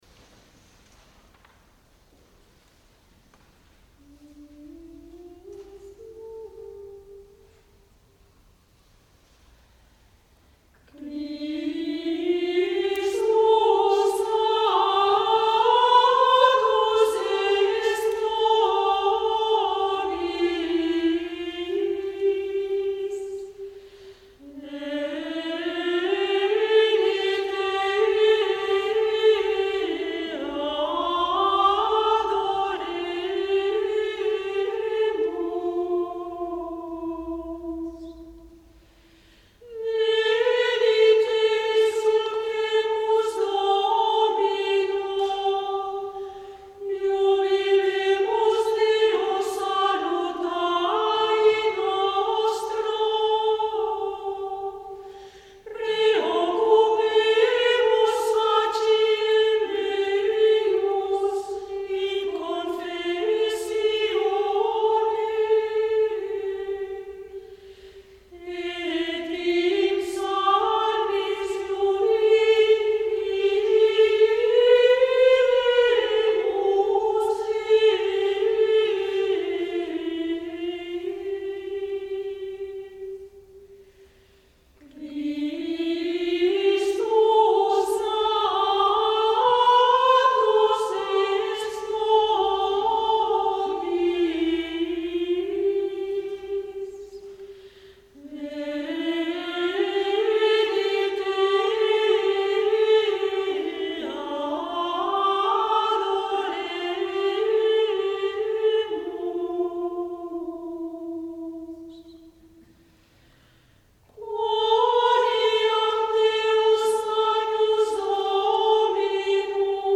Aquesta peça va ser recuperada i novament interpretada el 2013 pel cor femení Auditexaudi en el marc de l’audició Una passejada per la música monàstica del projecte Cants des del Claustre del Servei d’Arxius de la Federació Catalana de Monges Benedictines (SAF), antecessor de l’ACUB.
by Auditexaudi | Audició del SAF al Monestir de Sant Pau del Camp el 20 desembre 2023